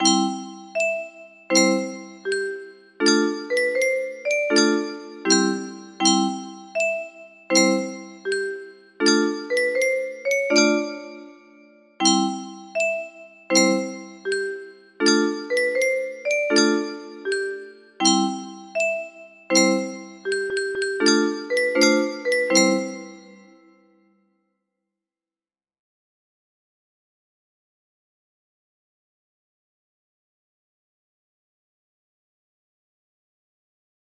Gracie's Song music box melody